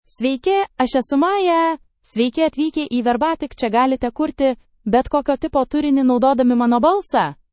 MayaFemale Lithuanian AI voice
Maya is a female AI voice for Lithuanian (Lithuania).
Voice sample
Listen to Maya's female Lithuanian voice.
Female
Maya delivers clear pronunciation with authentic Lithuania Lithuanian intonation, making your content sound professionally produced.